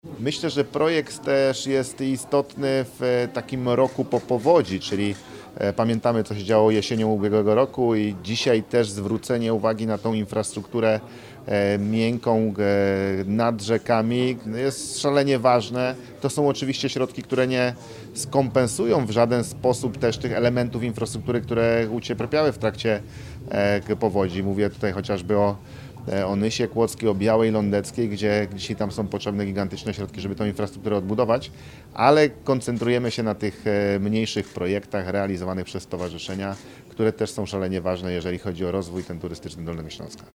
Marszałek podkreślił, że realizacja projektu w roku po ubiegłorocznej powodzi jest niezwykle istotna.